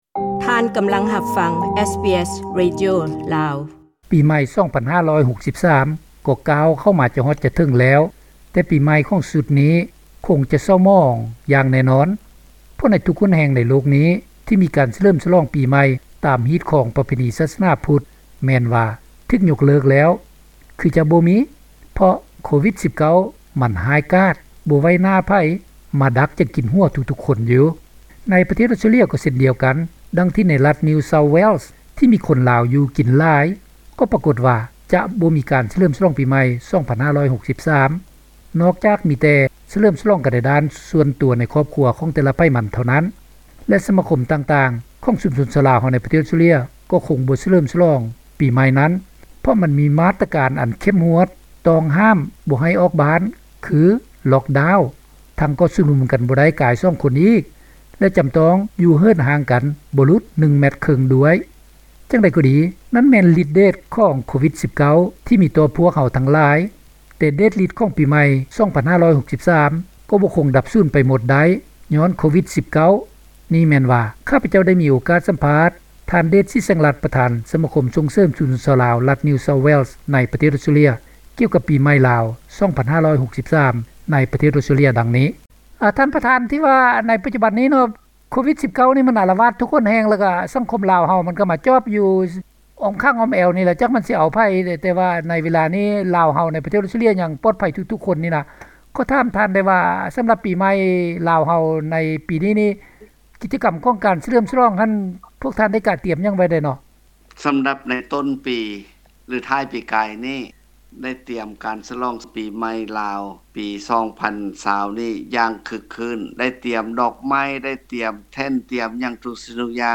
ໂກວິດ-19 ສັງຫານປີໃໜ່ລາວ 2563 ໃນອອສເຕຼເລັຍ (ສຳພາດ)